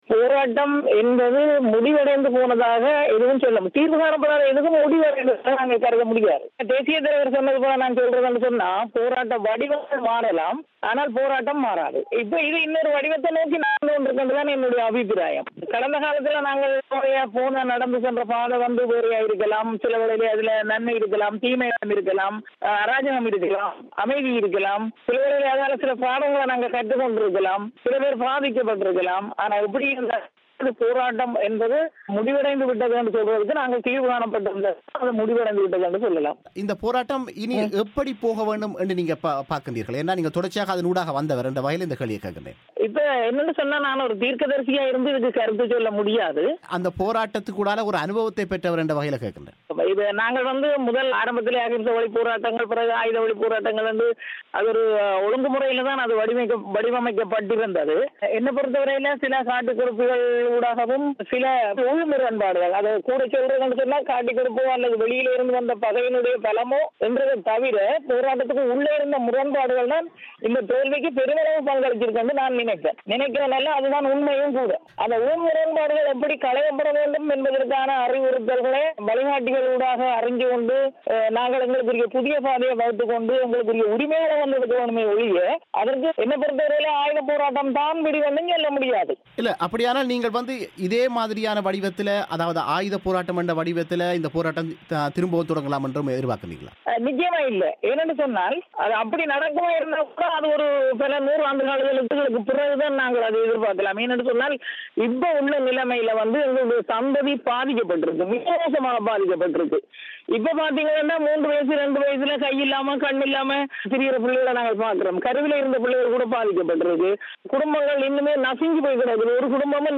அரசாங்க புனர்வாழ்வுத்திட்டம் முன்னாள் போராளிகளுக்கு போதிய உதவிகளை செய்யவில்லை என்று கூறும் அவர் ஊரில் எஞ்சியுள்ள போராளிகளின் குடும்ப நிலைமை மிகவும் மோசமாக இருப்பதாகவும் கூறுகிறார். இராணுவ பிரசன்னம், நில ஆக்கிரமிப்பு ஆகியவை குறித்தும் அவர் விமர்சிக்கிறார். அவர் பிபிசி தமிழோசைக்கு வழங்கிய முழுமையான செவ்வியை நேயர்கள் இங்கே கேட்கலாம்.